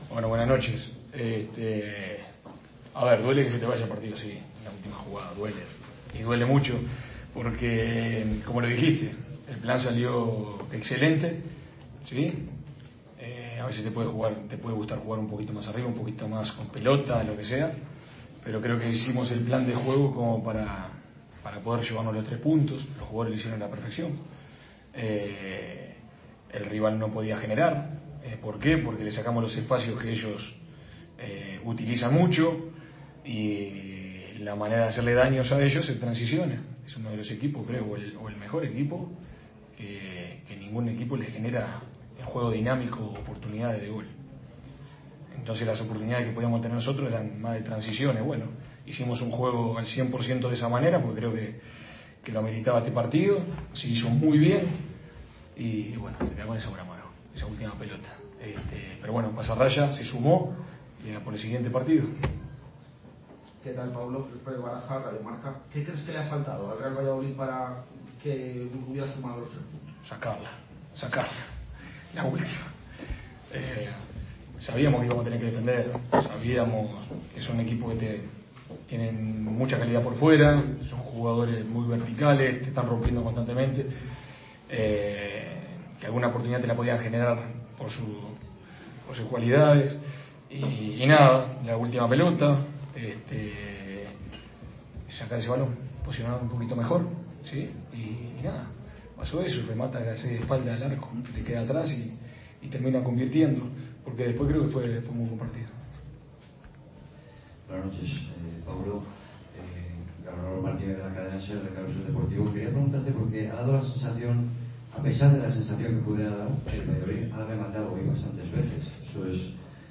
Ruedas de prensa
aquí la rueda de prensa completa